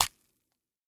На этой странице собраны разнообразные звуки, связанные с манго: от мягкого разрезания ножом до сочного откусывания.
Звук ножа пронзает манго